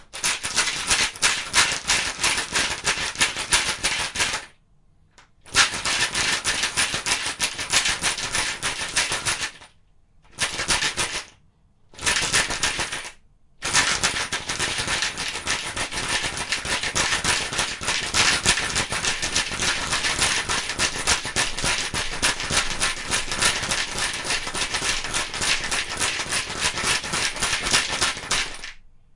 机器嘎嘎作响 - 声音 - 淘声网 - 免费音效素材资源|视频游戏配乐下载
摇晃的机器声音模仿在塑料容器内摇动的螺栓。